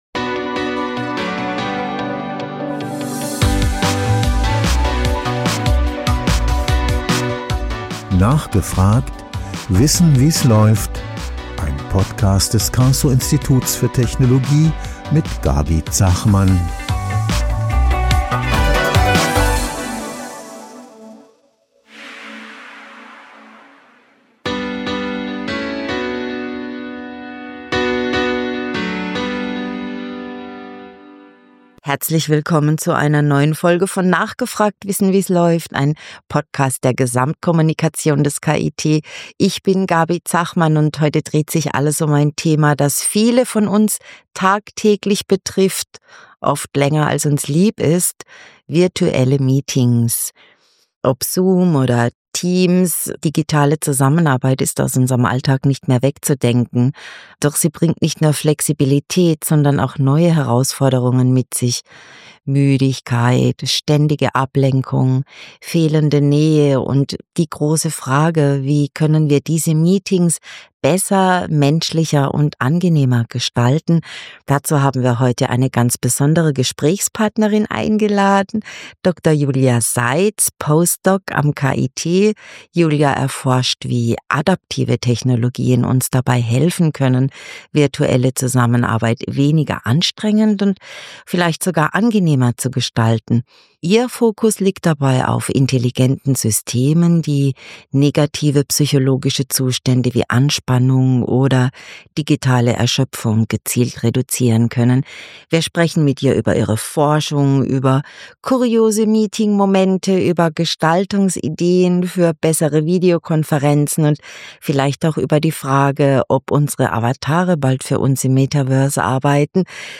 Im Interview-Podcast des Karlsruher Instituts für Technologie (KIT) sprechen unsere Moderatorinnen und Moderatoren mit jungen Forschenden, die für ihr Thema brennen. Die Wissenschaftlerinnen und WIssenschaftler stellen sich Fragen rund um ihr Fachgebiet und erklären komplexe Zusammenhänge einfach und verständlich.